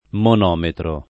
[ mon 0 metro ]